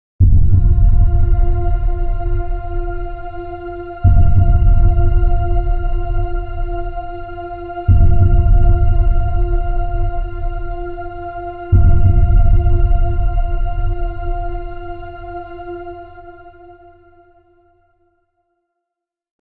Genres: Sound Logo Artist